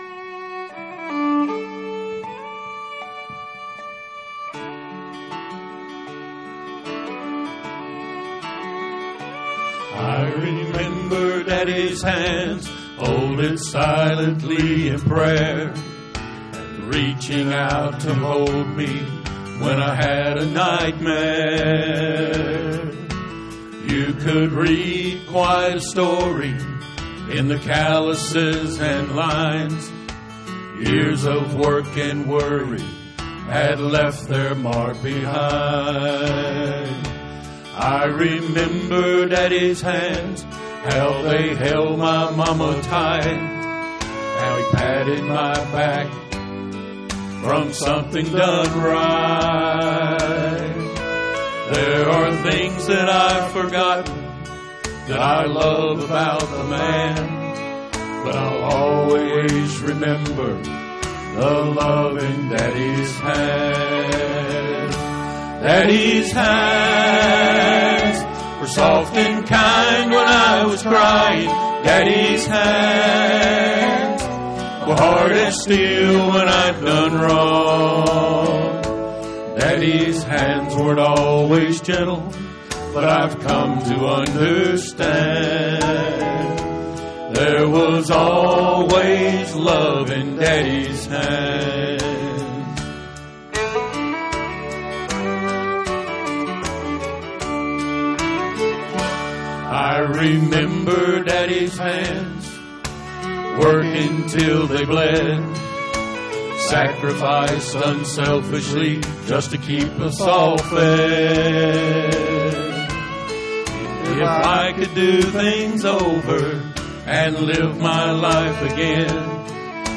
Special Music - Calvary Baptist Church